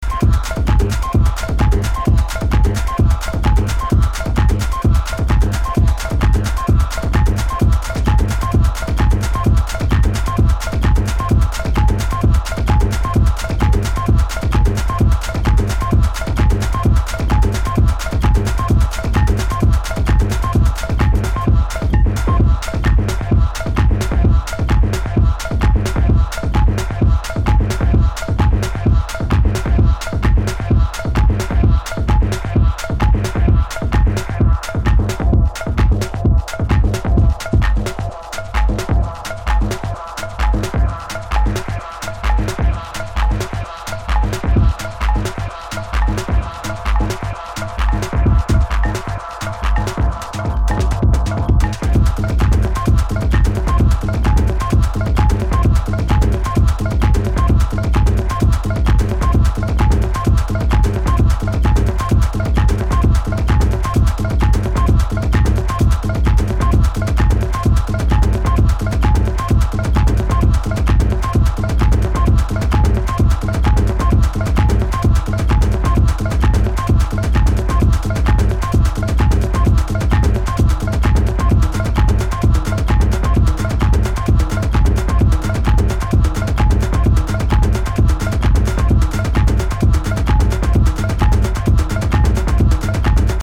supplier of essential dance music
Electro Techno Acid